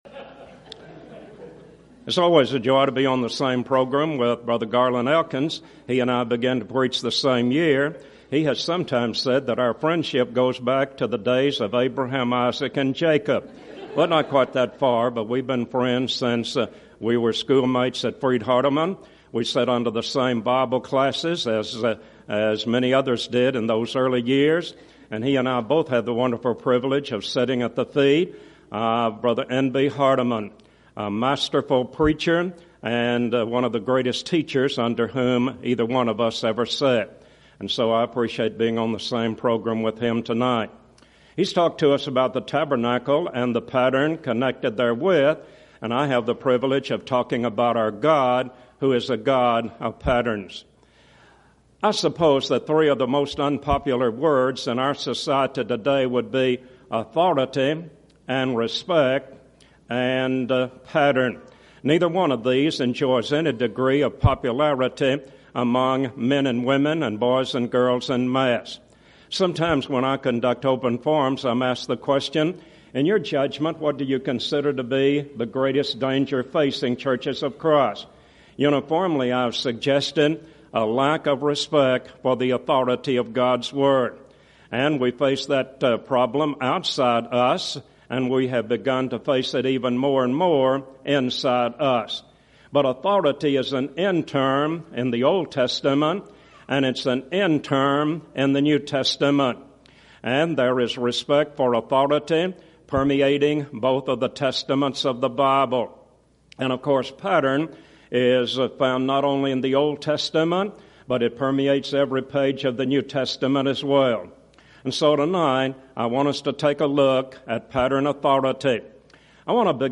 Event: 2nd Annual Schertz Lectures Theme/Title: Studies In Exodus
lecture